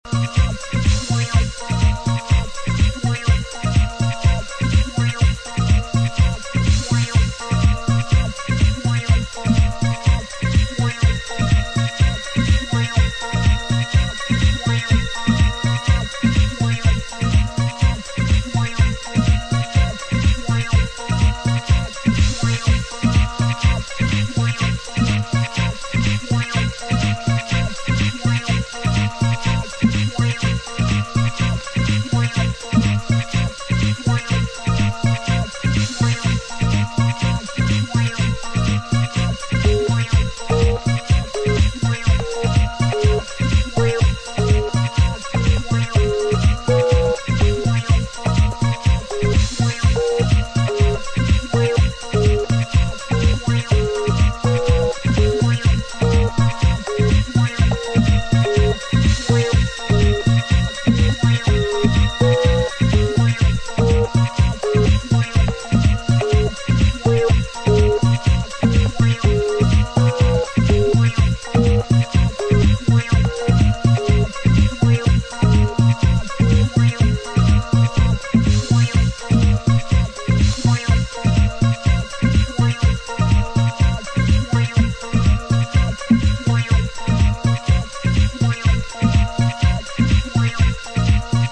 Pure Chicago housemusic bliss...!